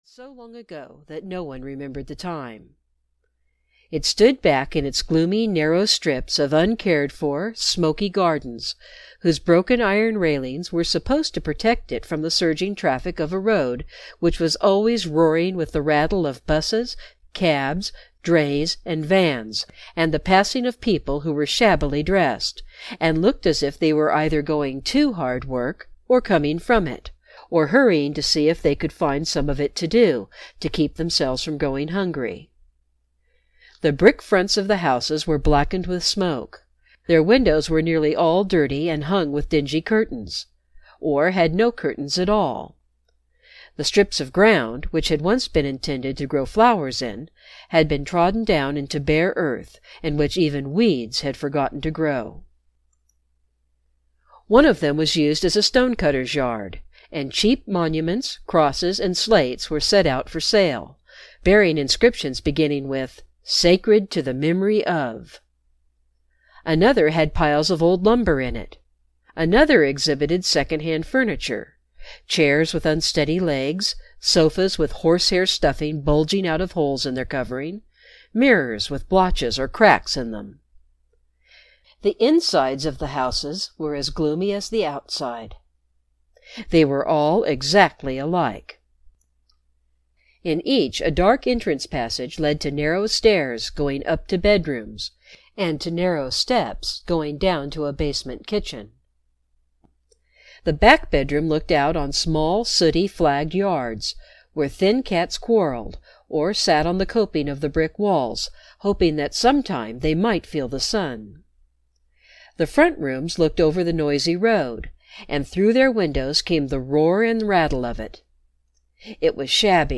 The Lost Prince (EN) audiokniha
Ukázka z knihy